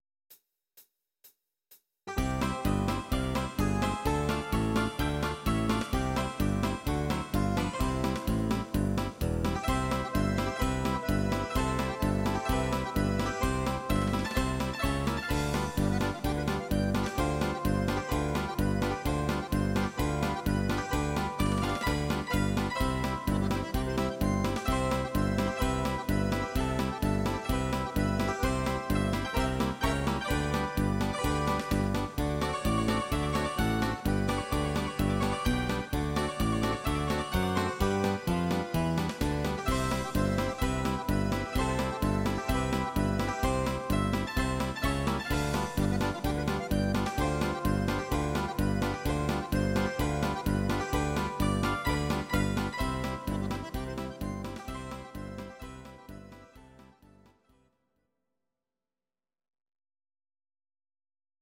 instr. Zither